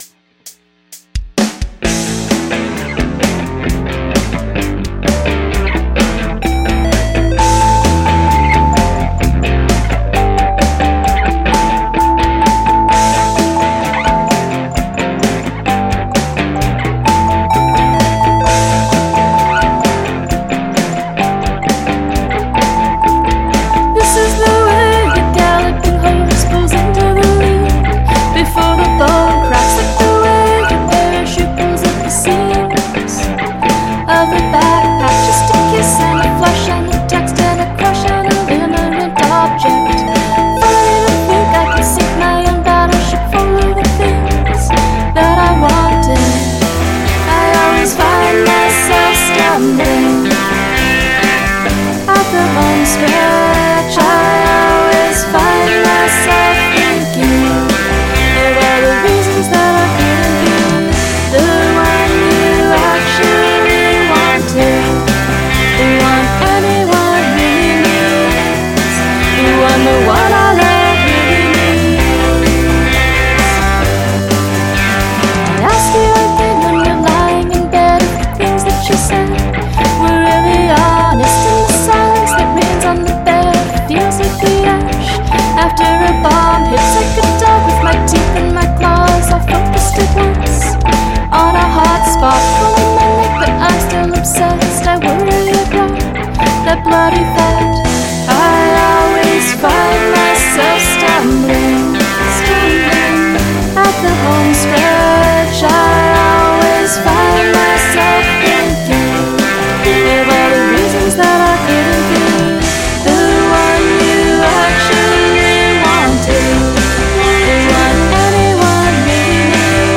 Polyrhythm